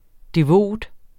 Udtale [ deˈvoˀd ]